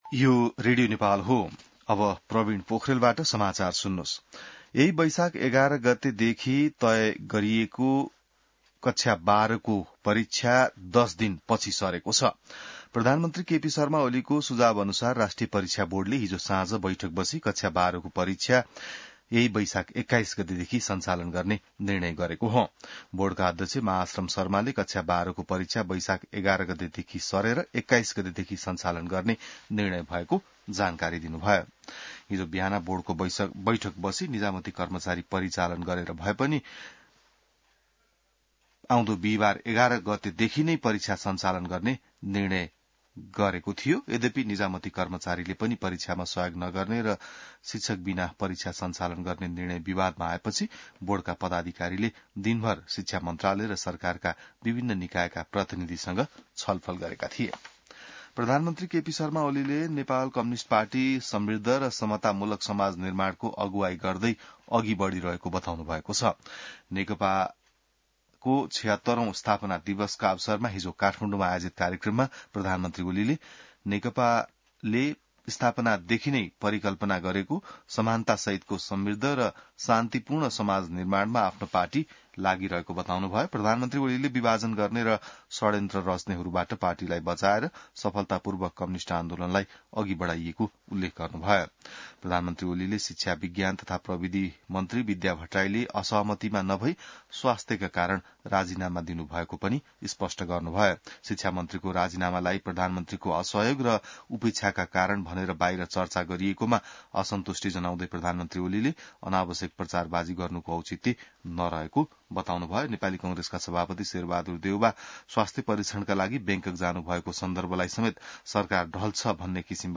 बिहान ६ बजेको नेपाली समाचार : १० वैशाख , २०८२